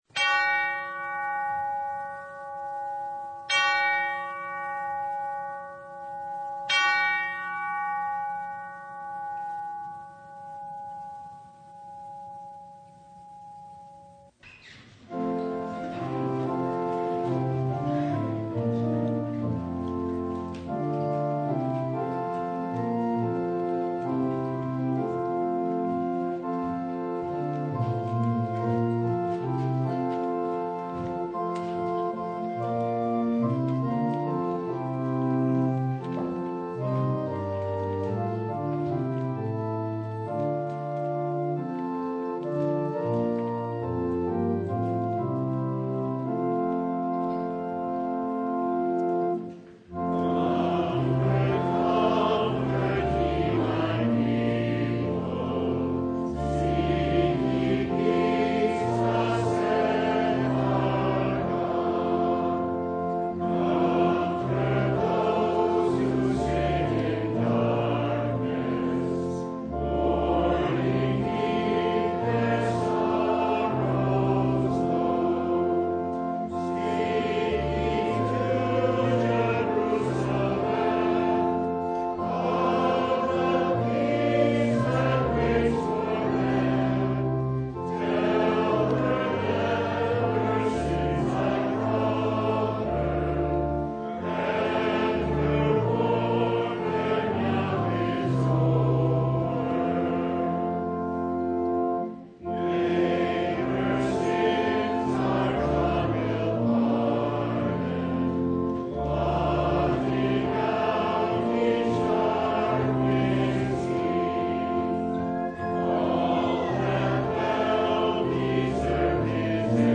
Passage: Matthew 3:1-12 Service Type: Sunday
Full Service